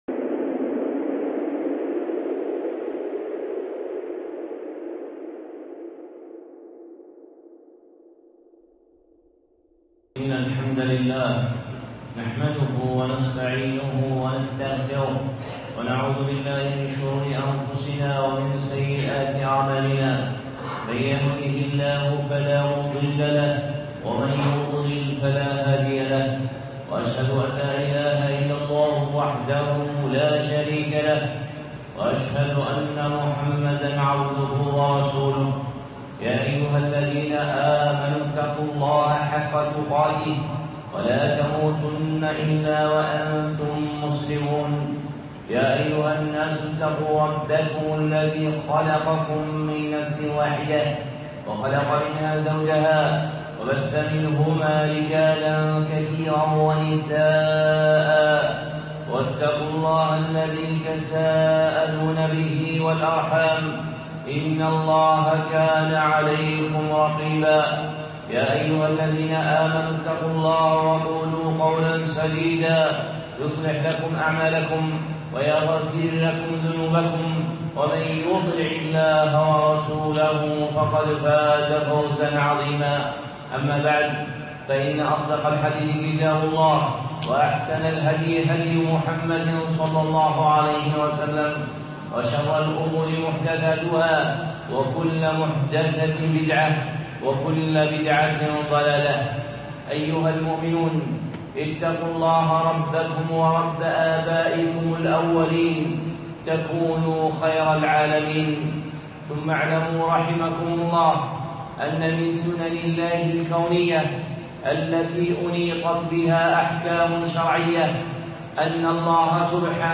خطبة (تعظيم الأشهر الحرم)